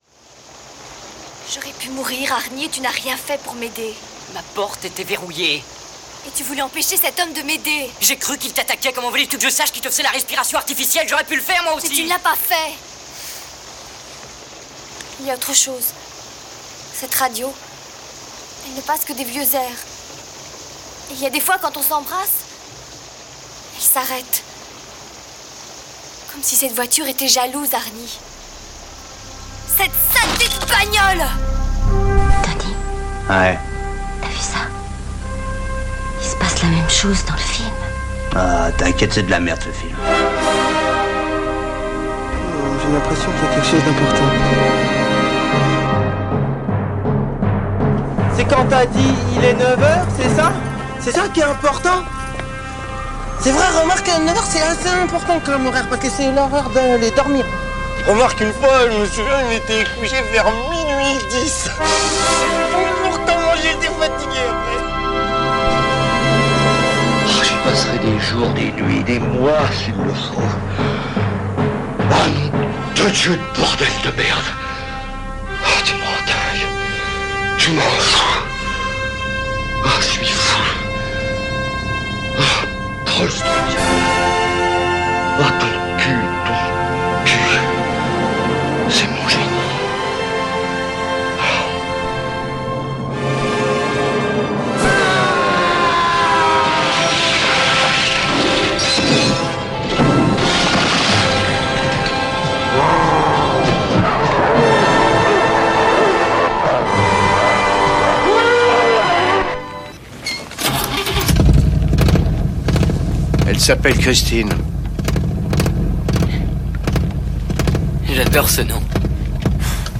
En avril 2025 lors de la Convention Cinéphile organisée par 97mm au Cinéma Arvor, Jean-Baptiste Thoret était venu présenter Christine pour promouvoir son excellent ouvrage Back to the Bone : John Carpenter 2025 (édition Magnani)
Voilà la captation de cette rencontre.